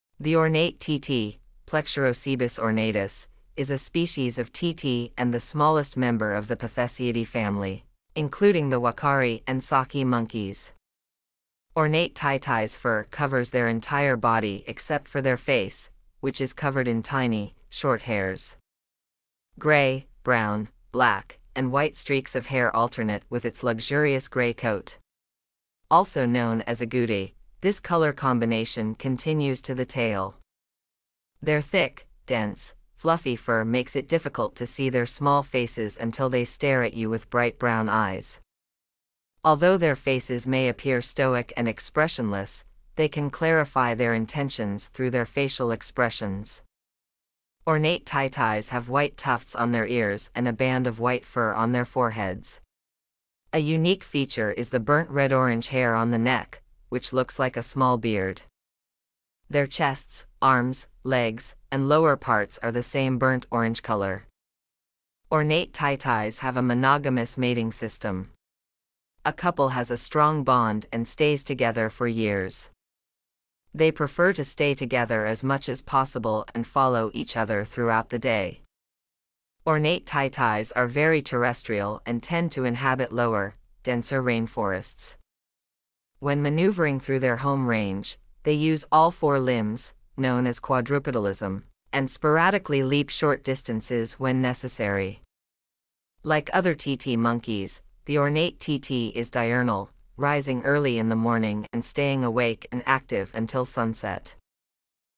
Ornate Titi
Animalia Primates Pitheciidae Plecturocebus Plecturocebus ornatus
Ornate-Titi.mp3